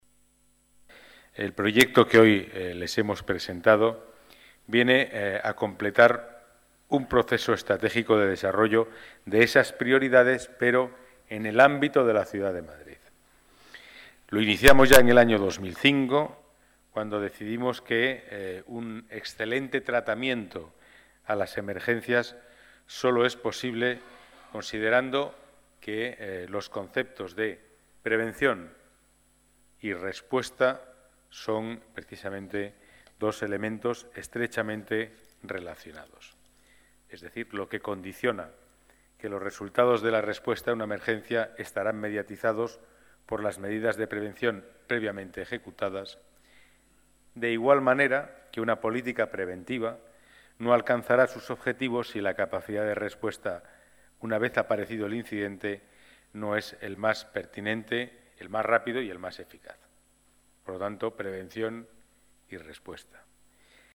Nueva ventana:Declaraciones delegado Seguridad y Movilidad, Pedro Calvo: Mapa de Riesgos de los distritos de Madrid